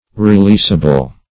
Releasable \Re*leas"a*ble\ (r?-l?s"?-b'l), a. That may be released.